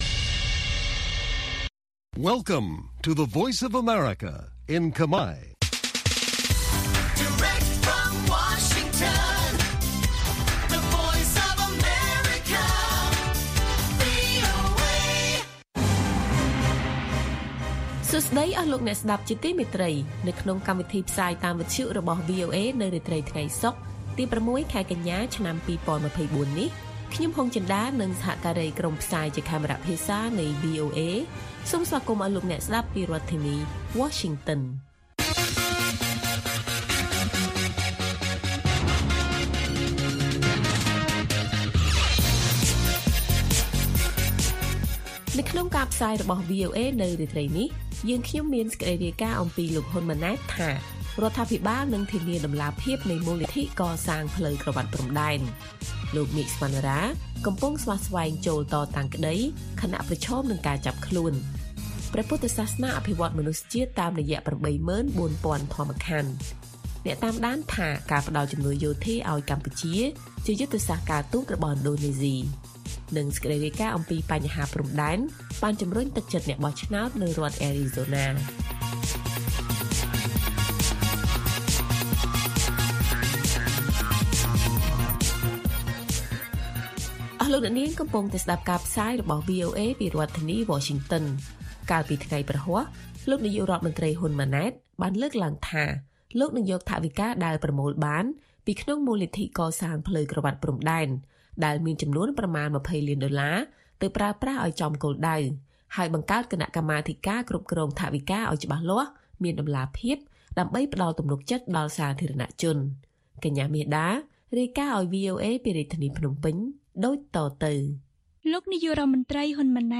ព័ត៌មានពេលរាត្រី ៦ កញ្ញា៖ លោក ហ៊ុន ម៉ាណែត ថា រដ្ឋាភិបាលនឹងធានាតម្លាភាពនៃមូលនិធិកសាងផ្លូវក្រវាត់ព្រំដែន